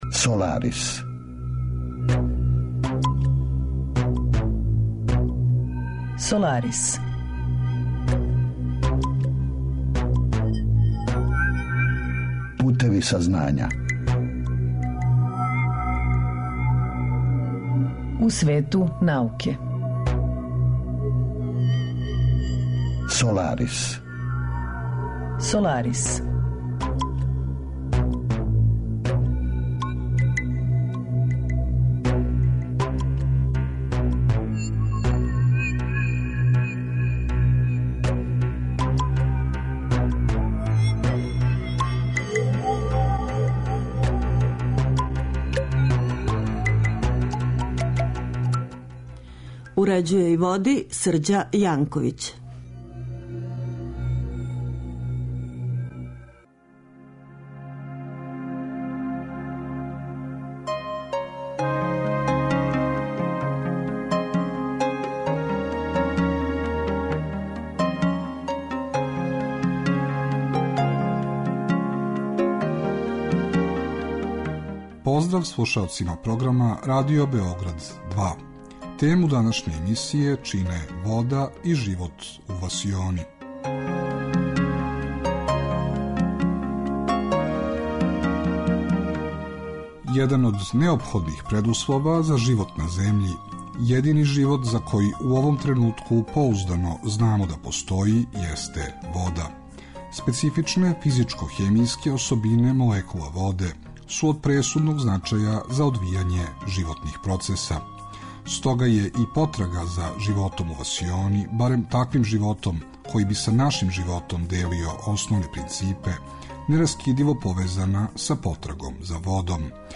Разговор је први пут емитован 9. септембра 2015.